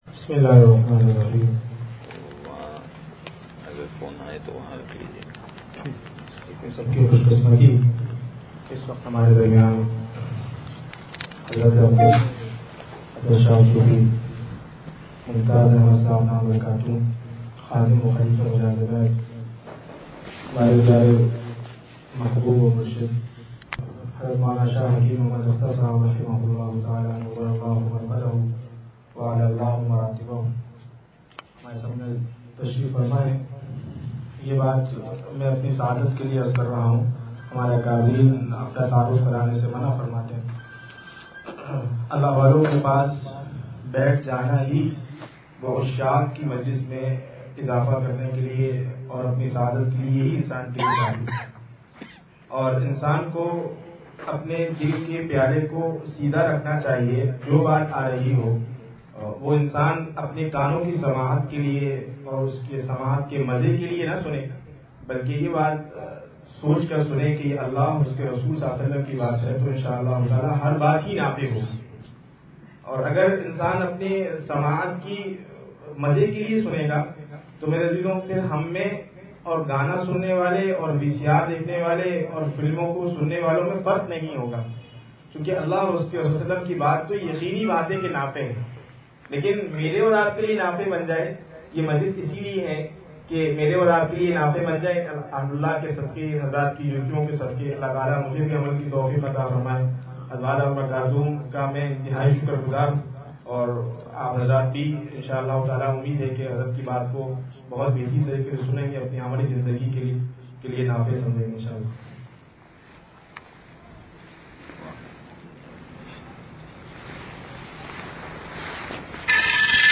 بیان بعد نماز ظہر مسجد رب العالمین سرجانی ٹاؤن – اتوار